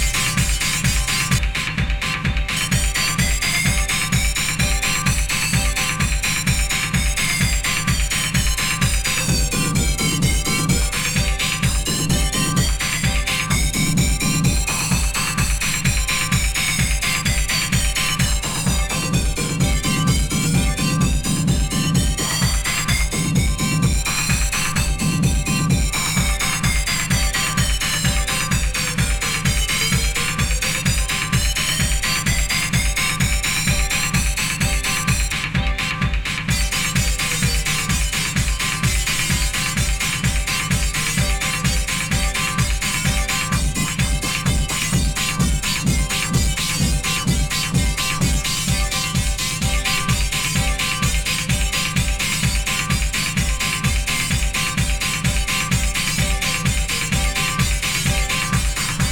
ハットが耳に刺さる！